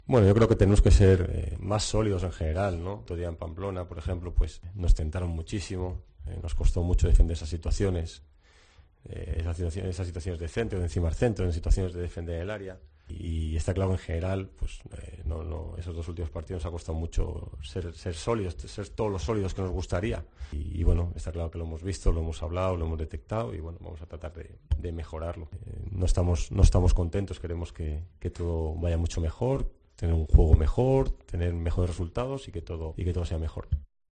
“Hemos dado una imagen que no nos gusta en los últimos dos encuentros”, señaló Celades en rueda de prensa en referencia a los partidos en los campos del Lille y del Osasuna.